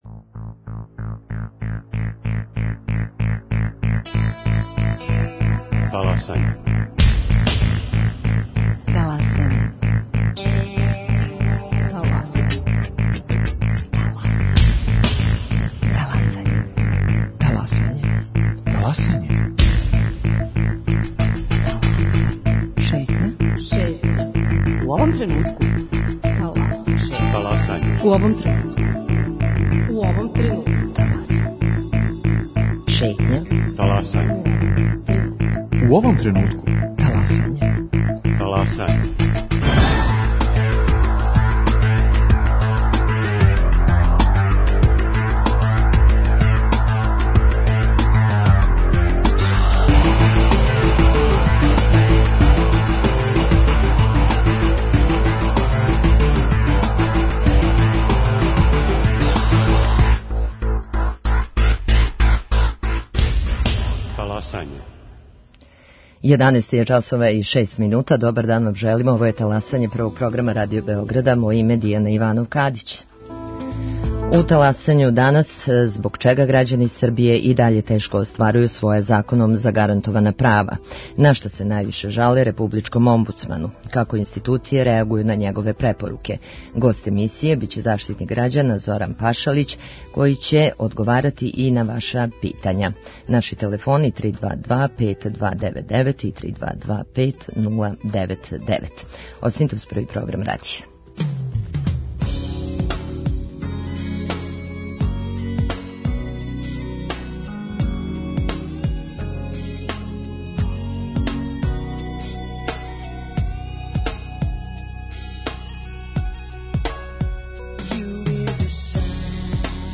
Гост: заштитник грађана Зоран Пашалић, који ће одговарати и на ваша питања.